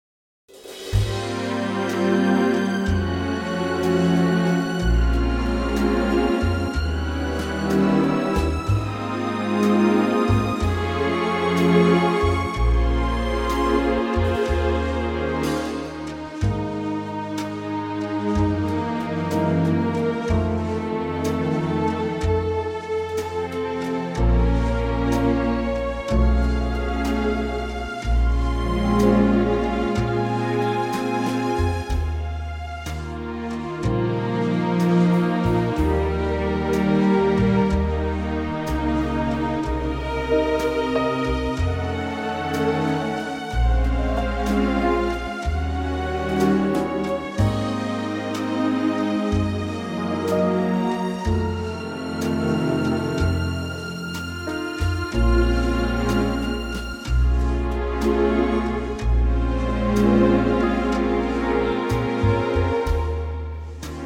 key - Ab - vocal range - Eb to Ab